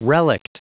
Prononciation du mot relict en anglais (fichier audio)
Prononciation du mot : relict